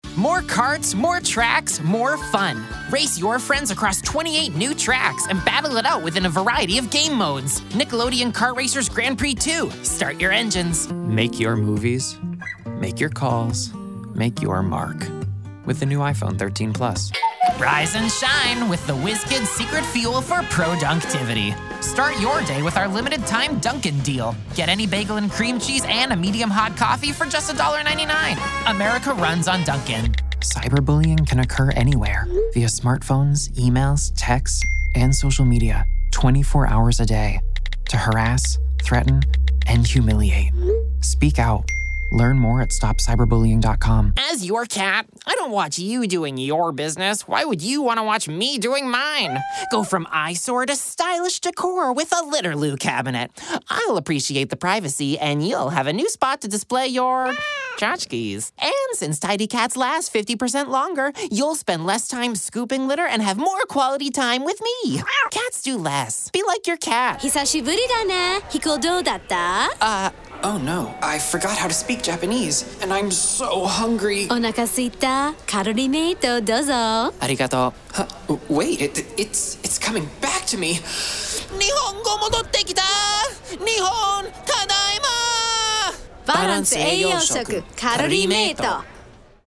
Voiceover : Animation